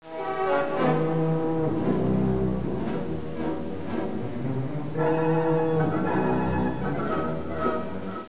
なお、音質は著作権への配慮で、ノイズを付加したものです。
コーダは最初第一主題に基づき荘厳に始まり、
鞭の音(179K)も聞こえますが、途中以降は第二主題に基づいた明るく力強いものとなり、長短移行和音も登場し、最後は華々しく結ばれます。